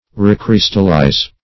Search Result for " recrystallize" : The Collaborative International Dictionary of English v.0.48: Recrystallize \Re*crys"tal*lize\ (r[=e]*kr[i^]s"tal*l[imac]z), v. i. & t. (Chem.